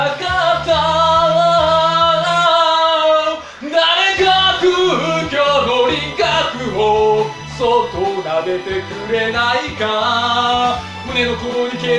で、ひととおり操作してみて、行きつけのカラオケ屋で自分の歌を録音してみた。
普段から、エコーに頼らない歌唱を目指しており、カラオケ屋のマイクは使わず生声なので、これもそうだ。